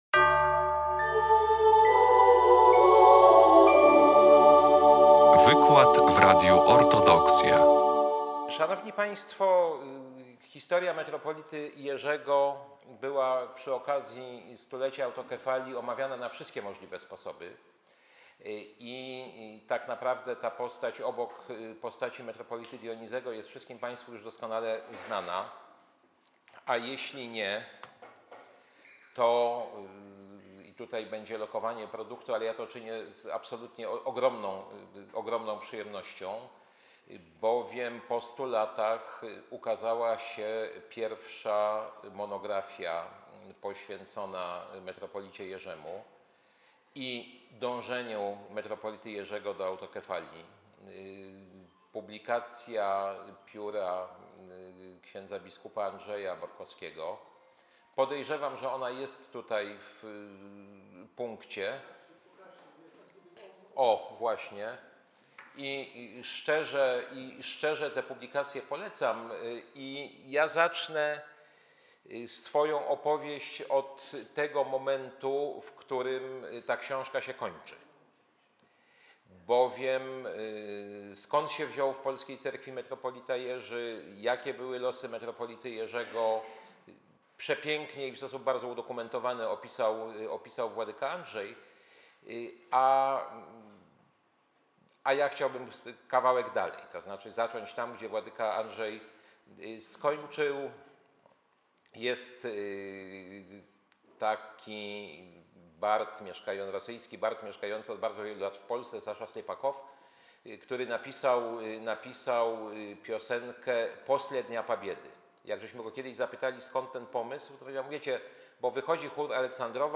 16 lutego w Centrum Kultury Prawosławnej w Białymstoku odbyło się spotkanie z cyklu Wszechnicy Kultury Prawosławnej 2025/2026.